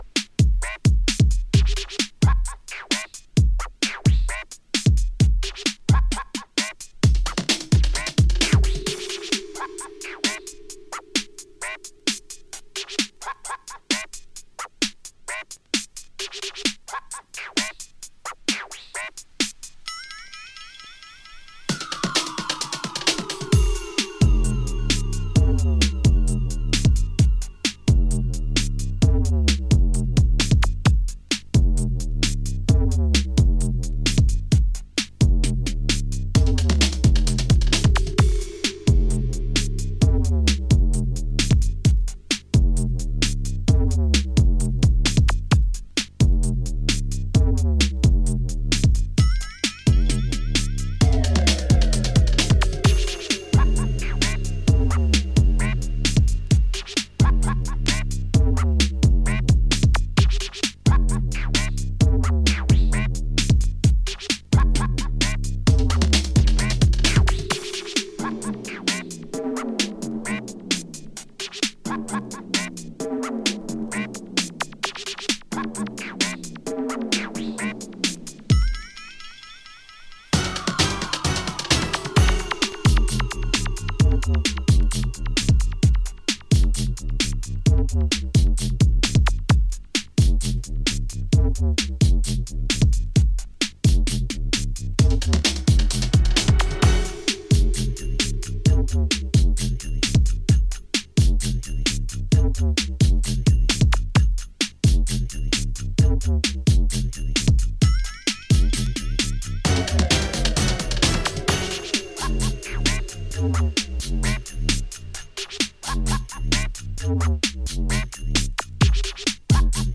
お茶目なスクラッチとオーケストラヒットがいいムード、雷が落ちたかのようなサブベースのエレクトロ
ユーモラス、かつ黒い狂気が充満している傑作です。